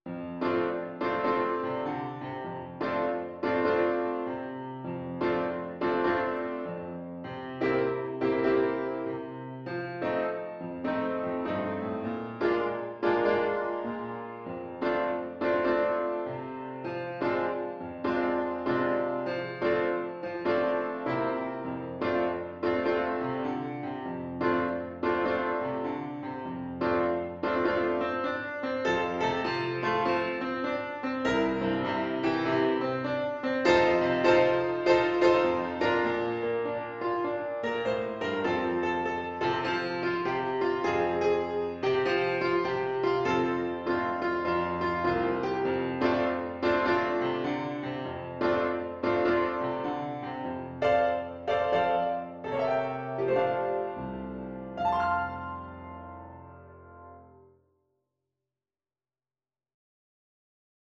4/4 (View more 4/4 Music)
Moderate swing